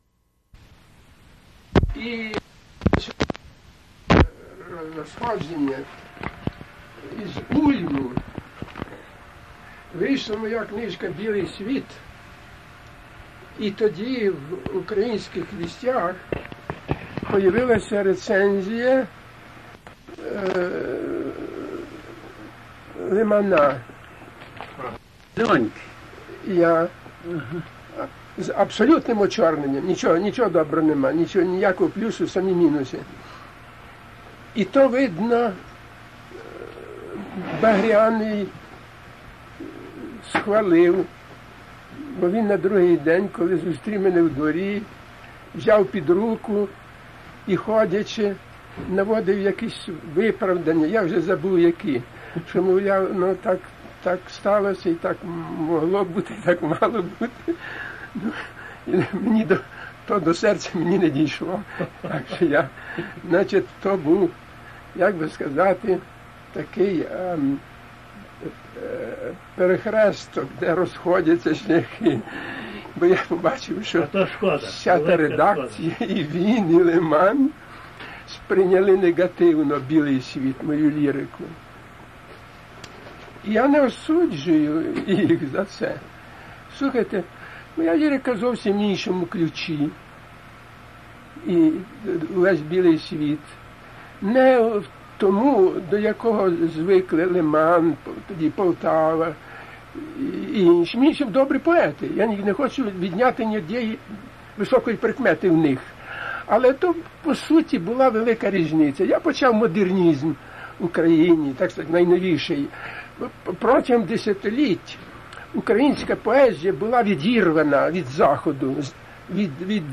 Vasyl' Barka interview